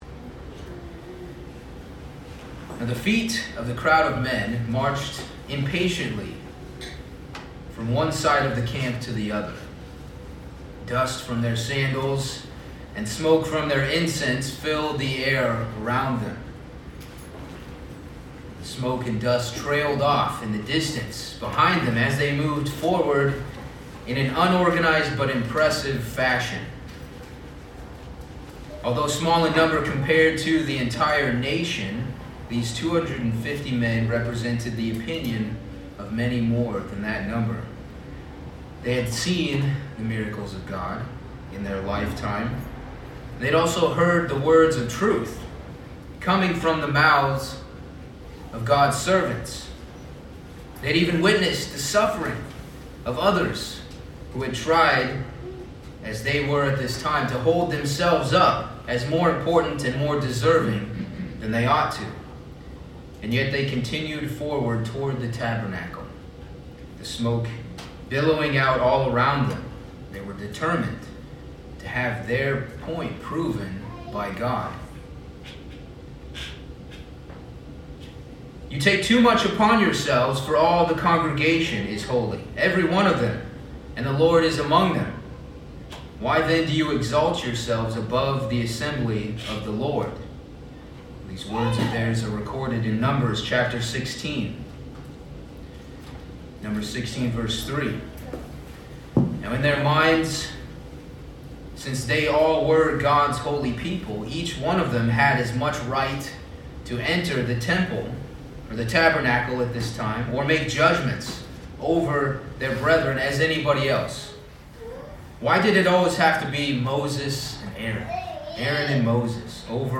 This sermon covers four acts of humility that Jesus demonstrated.